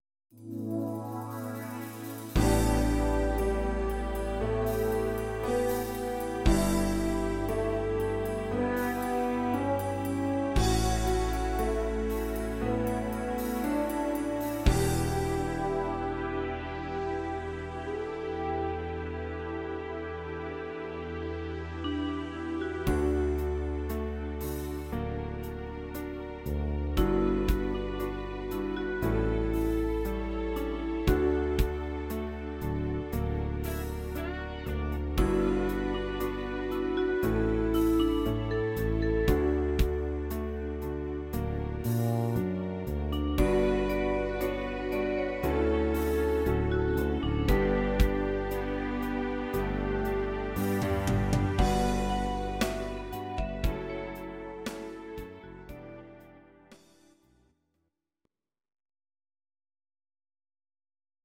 Audio Recordings based on Midi-files
Ital/French/Span, 1970s